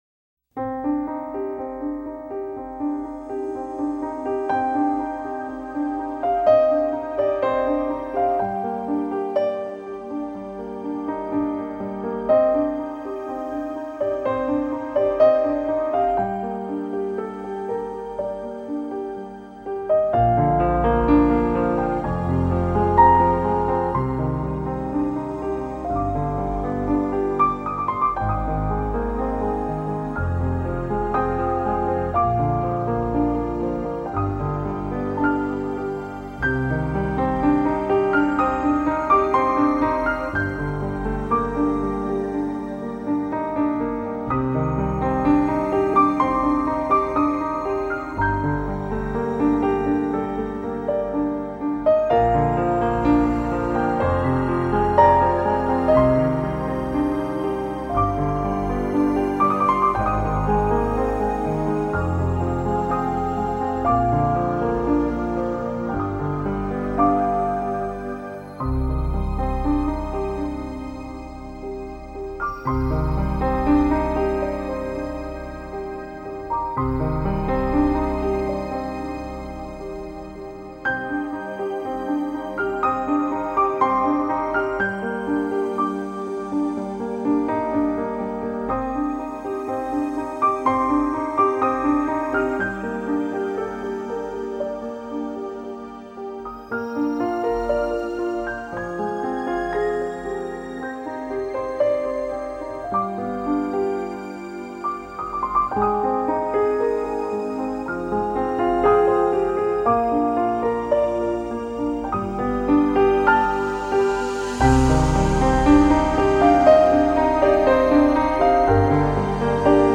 מרגש.mp3